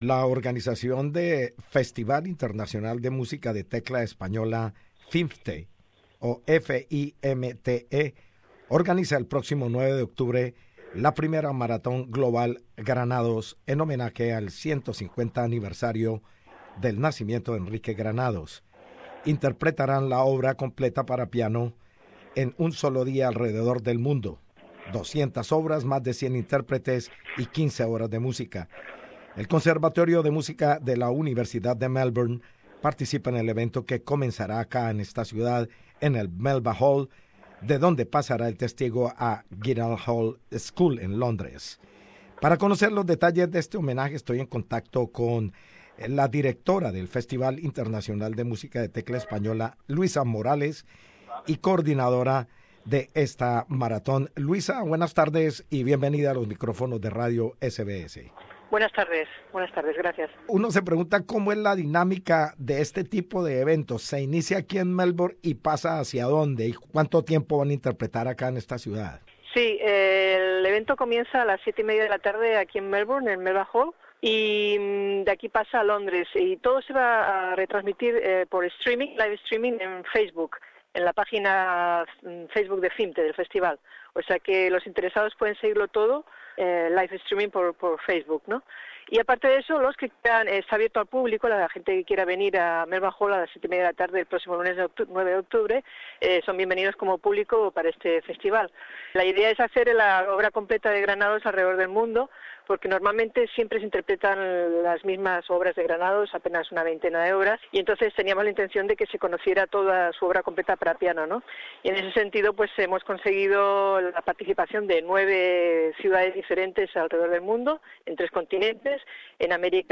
Enrique Granados es uno de los grandes innovadores musicales españoles, especialmente en lo que se refiere a su obra para piano. Ahora la organización FIMTE realiza una maratón mundial sobre la música de Granados, como homenaje a la celebración de los 150 años de su nacimiento. Entrevistamos